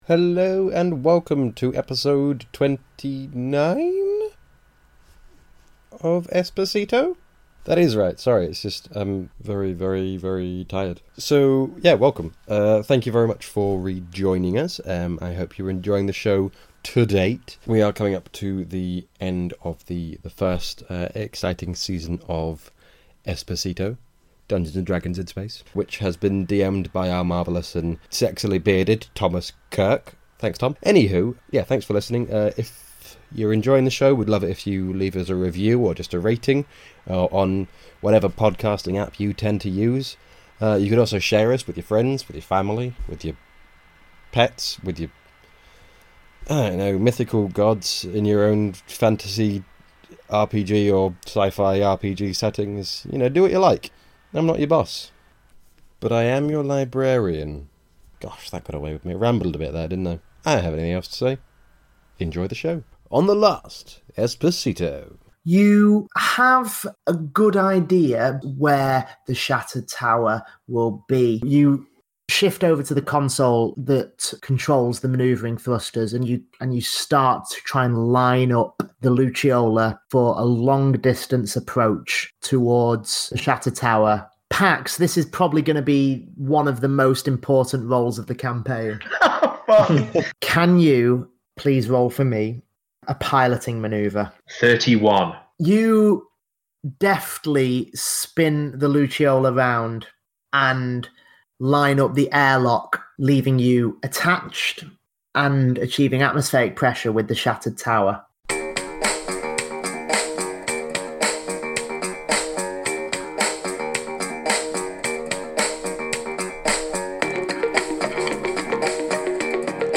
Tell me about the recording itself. This, the first live action Esper Genesis podcast, tells the ongoing saga of The Epsilon Shift, an innocent group of idiots who have been framed for a terrorist attack.